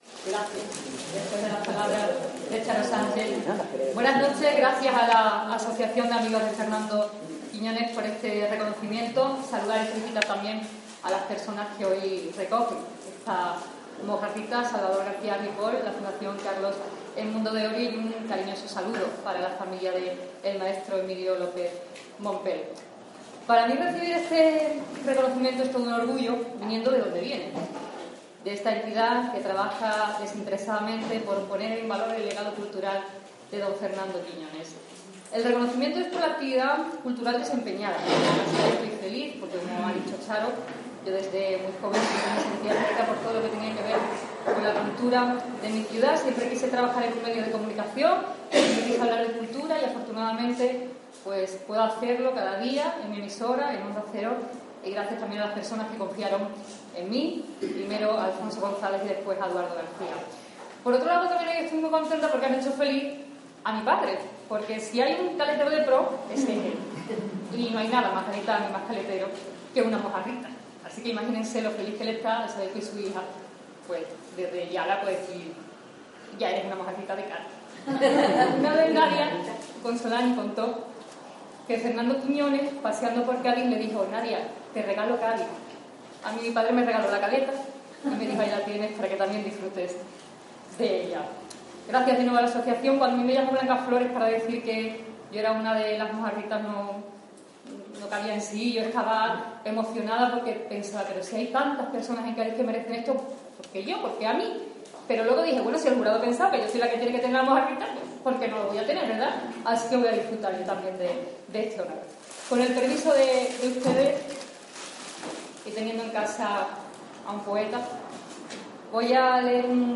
Mojarritas de Plata 2015. Acto de entrega el 29 de septiembre en el Centro Cultural Reina Sofía de Cádiz.